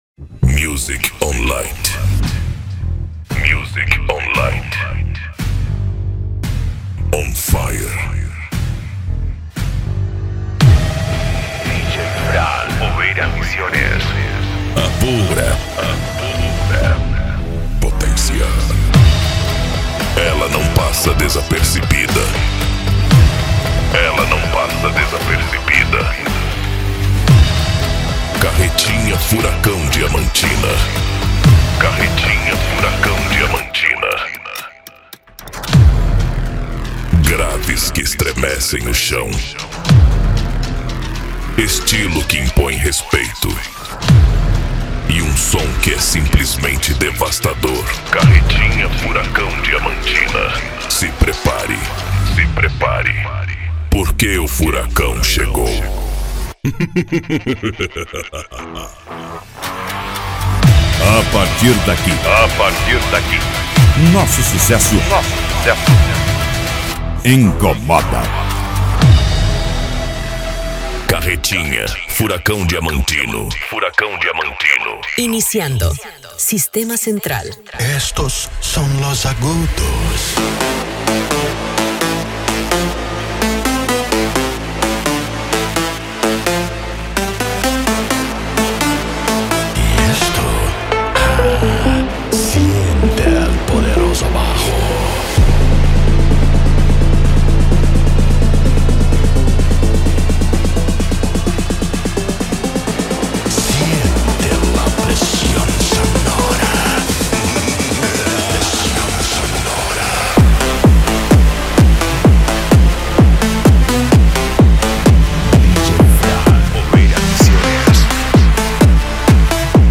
Bass
PANCADÃO
Remix
Trance Music